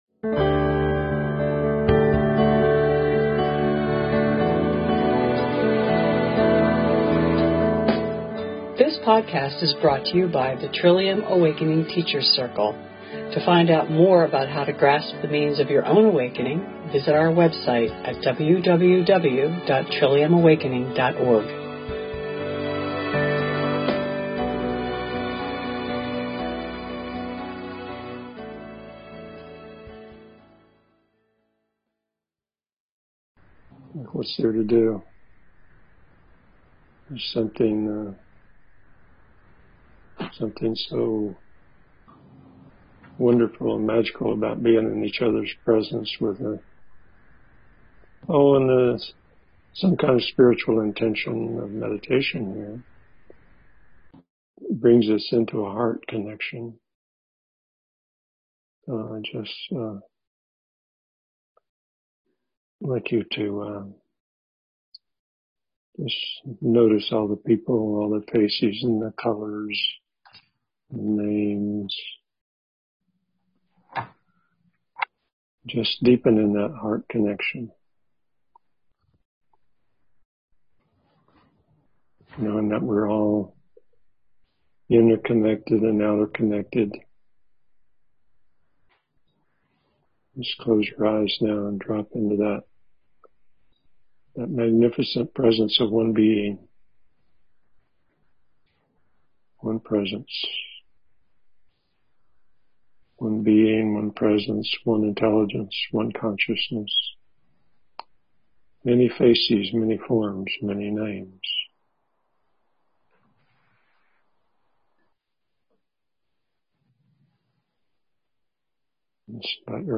Deepen in Heart Connection - Guided meditation